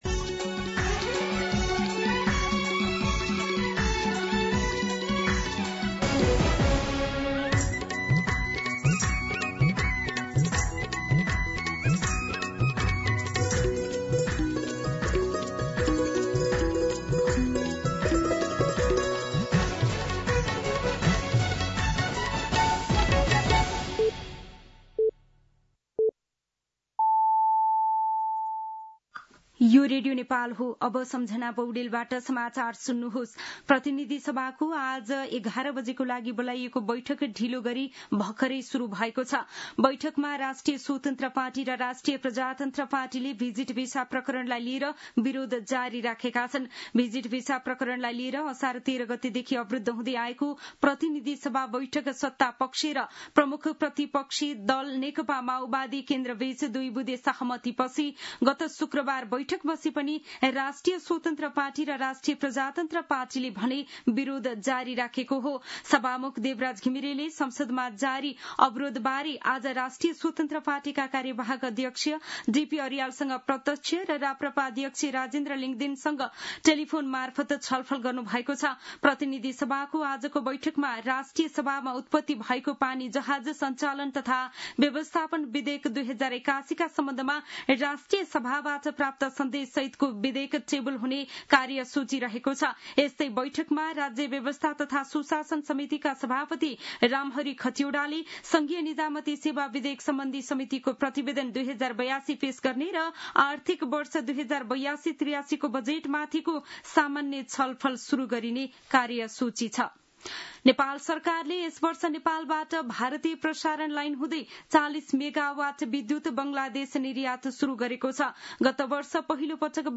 दिउँसो १ बजेको नेपाली समाचार : १ असार , २०८२